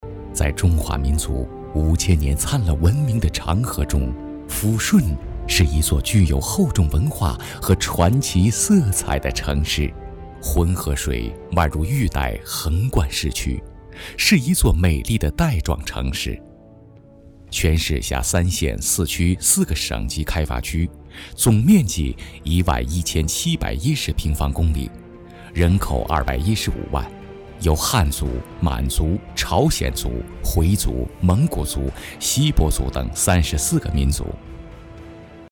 城市宣传片男315号
轻松自然 城市形象
磁性稳重男音，擅长自然讲述，厚重历史解说、党委宣传等题材，作品：抚顺城宣。